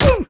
gen_die1.wav